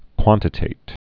(kwŏntĭ-tāt)